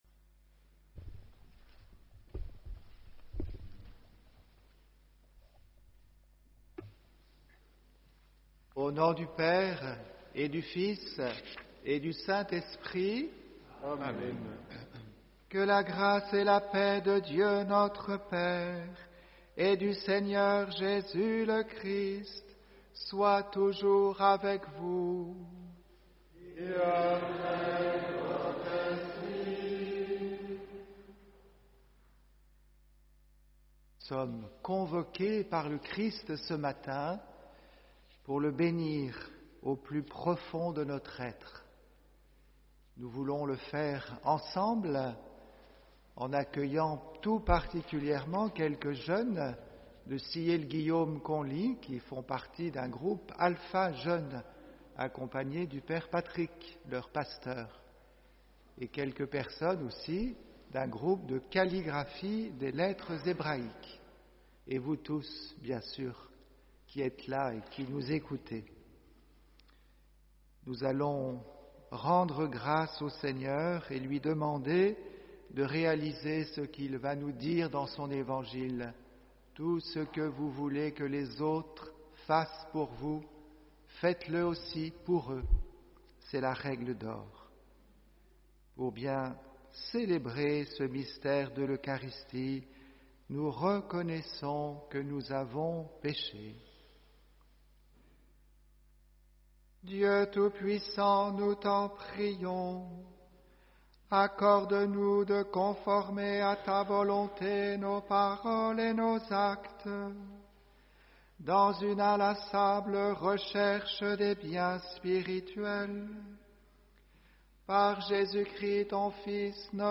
HOMELIE